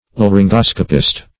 Laryngoscopist \Lar`yn*gos"co*pist\, n. One skilled in laryngoscopy.
laryngoscopist.mp3